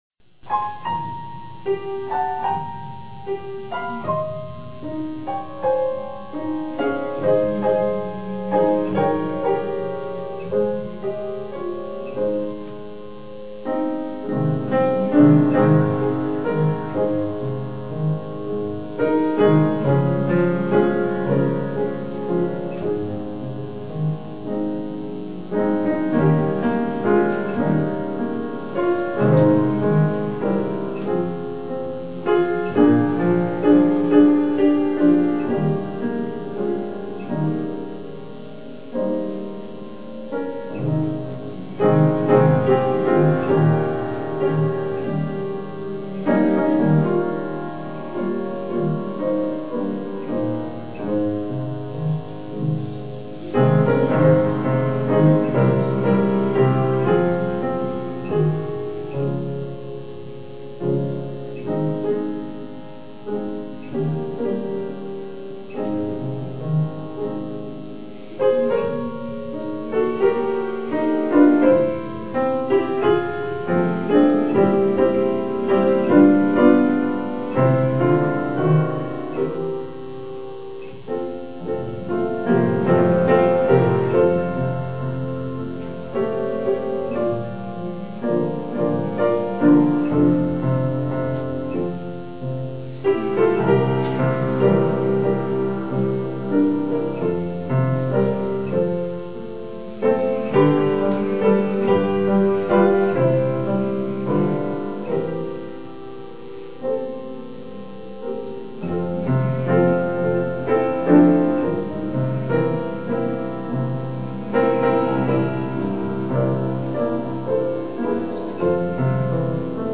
PLAY Fifth Sunday Night Sing
2007 "In the Garden," piano solo
vocal duet
guitar accompaniment
Guitar and vocal solo
vocal and banjo